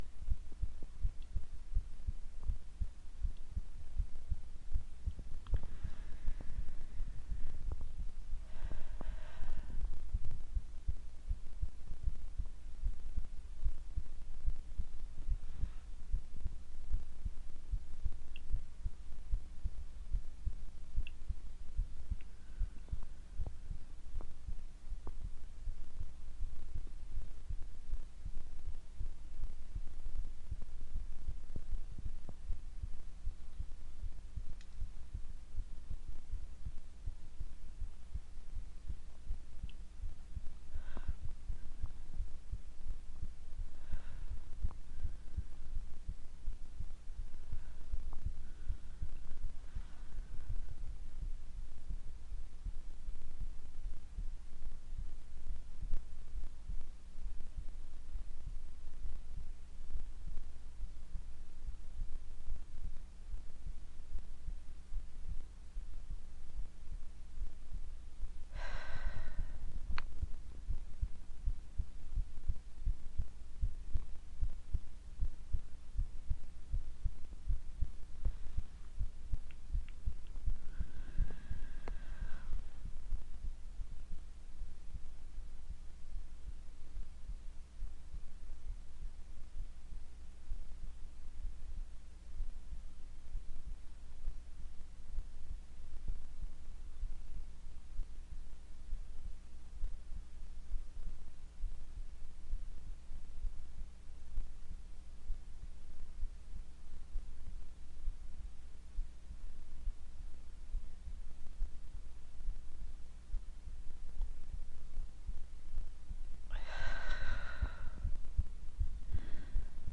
为了创建这个心电图，我生成了一个频率为1500，幅度为0.3的正弦波，持续时间为0.5秒。我以不同的间隔重复这个声音15次。我再次产生了一个正弦波，但这次是在5秒的时间内再现心电图停止的心脏声。为了减少声音之间的截止效果，我手动改变了音量以创建淡入和淡出。
然后我用一个混响效果来升华整个事物。